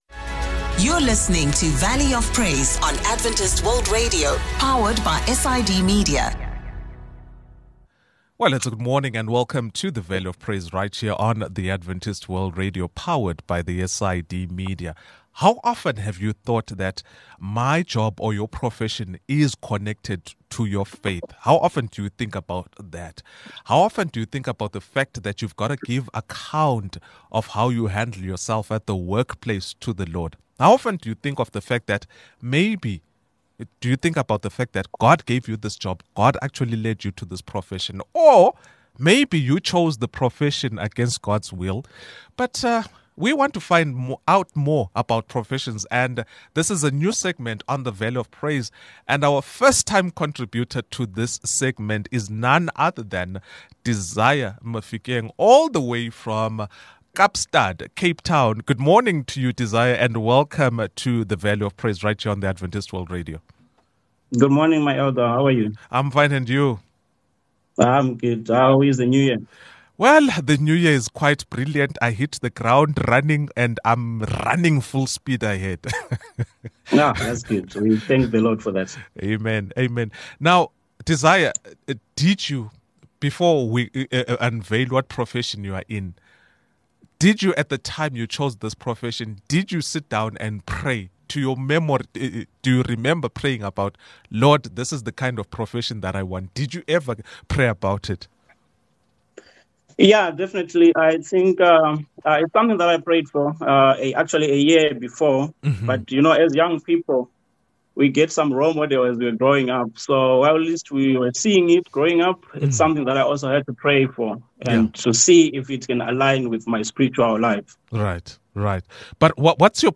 In this episode of Faith Meets Profession, we sit down with a talented chef who brings faith into the kitchen. From recipes to principles, discover how they balance passion and purpose, cooking not just for the body, but also for the soul. Tune in for a conversation about integrating faith and profession in the world of culinary arts